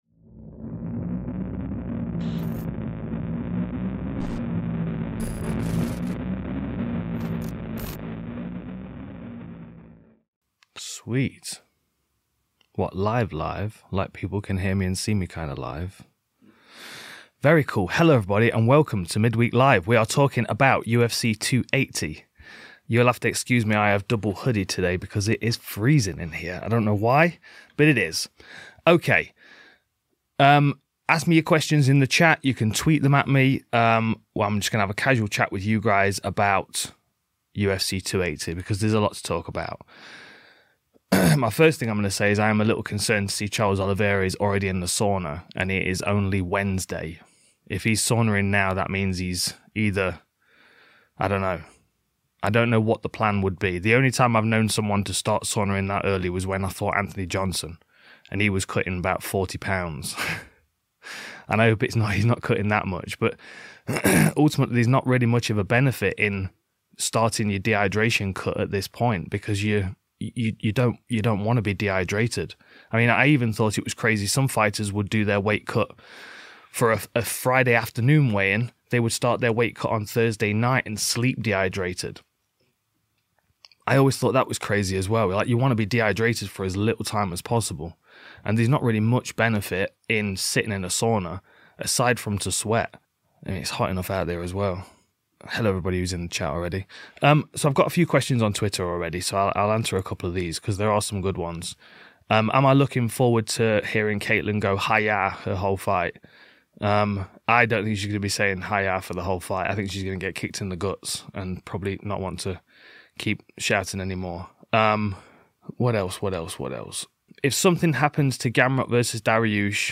Join Dan Hardy Live as he answers all your questions and makes predictions for UFC 280: Charles Oliveira vs Islam Makhachev.